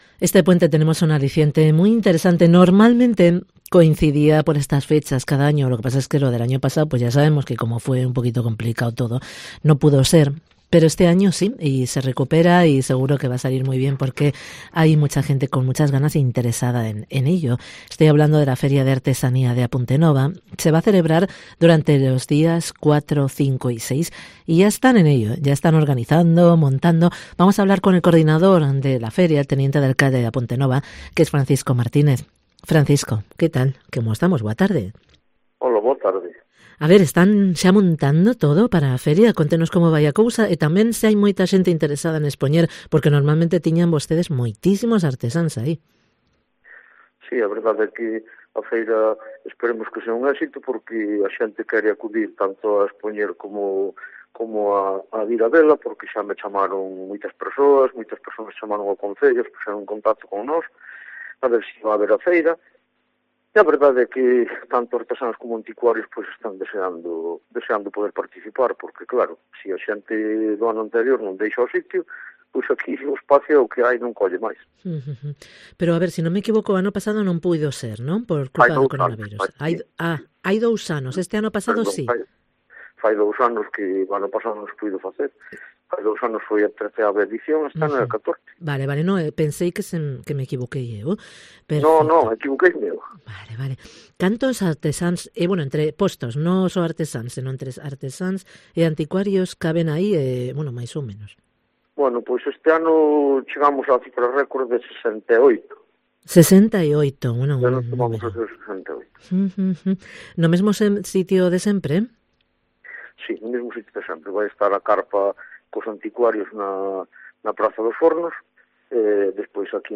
También demostraciones en directo de oficios de h ierro, telares, cestería de mimbre o cuero, como explica el coordinador de la feria y teniente de alcalde, Francisco Martínez.